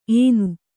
♪ enu